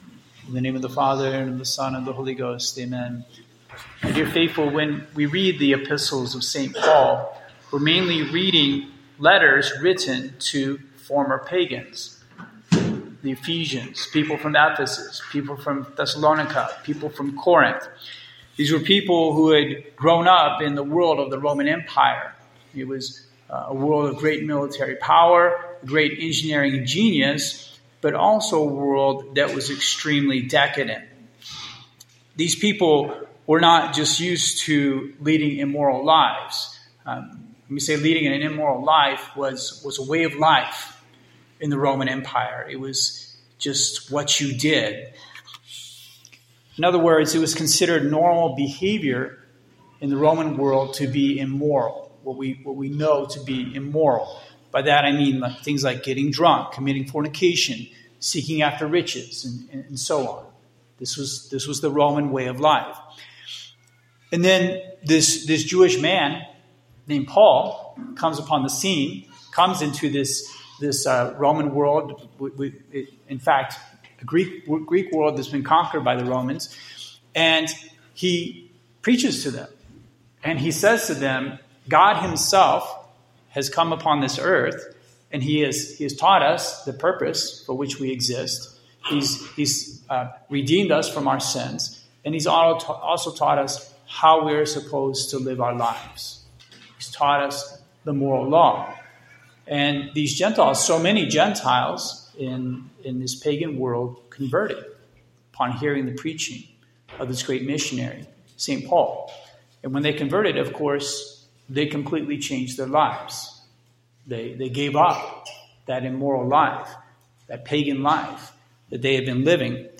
Pagan Impurity, Sermon